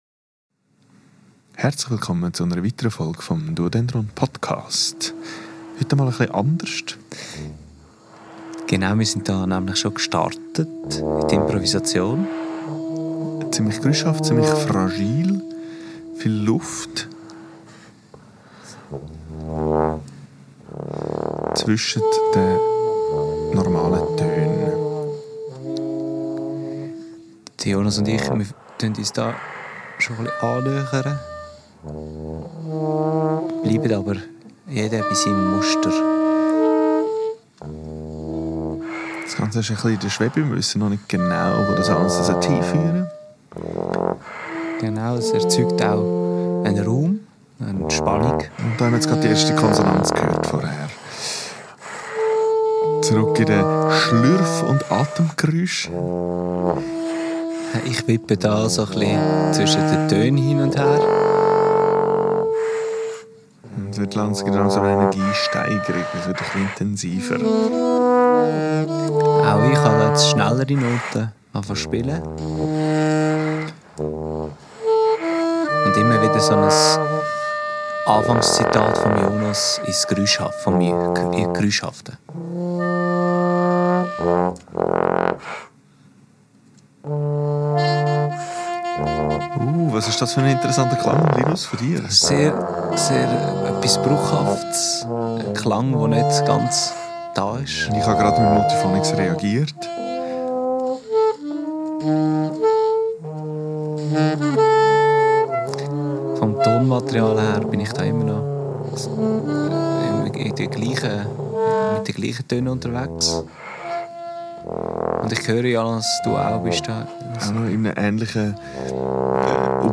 Endlich wieder Mal eine Folge mit mehr Musik als Gelaber!
Live-Kommentar und Analyse über Gespieltes und das ganze auf Schweizerdeutsch.